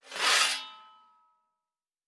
Blacksmith 09.wav